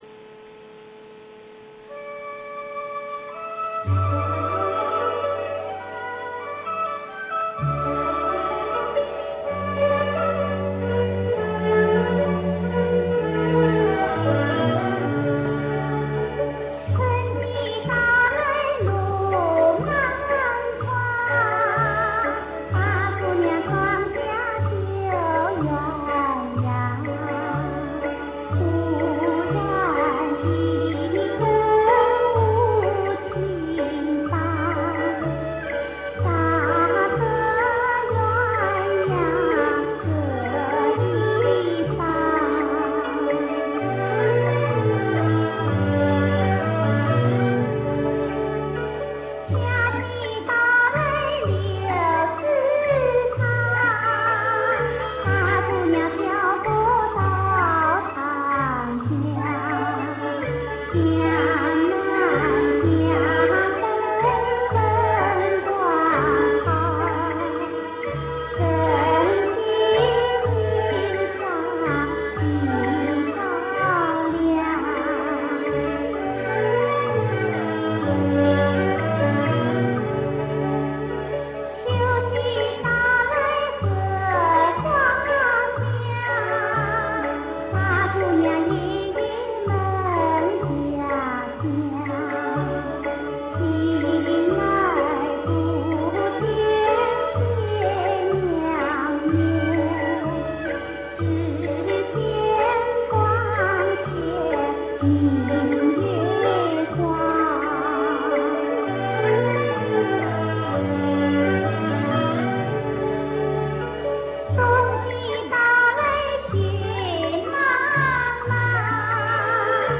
Music -- Traditional Chinese Music
Four Seasons: a song with vocals and music. Notice the singer's style of singing and the background music played by traditional instruments.